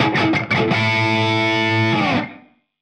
Index of /musicradar/80s-heat-samples/85bpm
AM_HeroGuitar_85-A02.wav